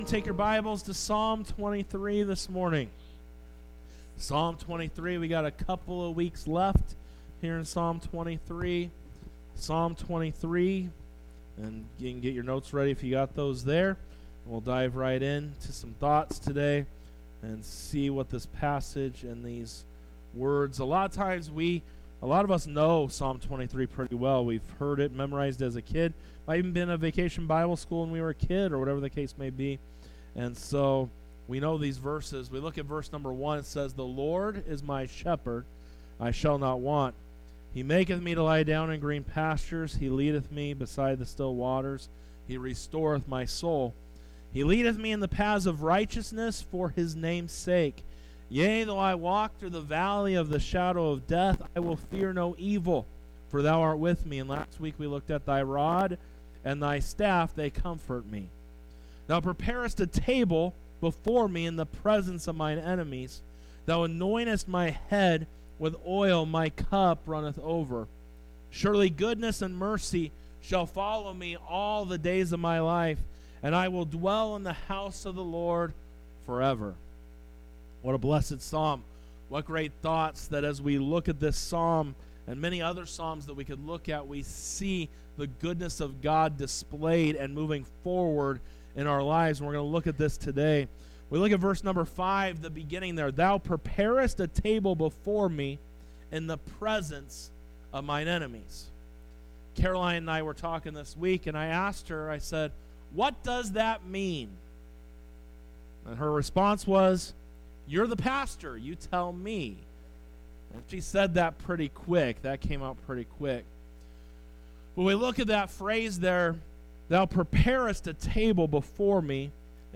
Sermons | Victory Baptist Church
Sunday Worship Service 06:30:24 - Thou Preparest A Table by vbcchino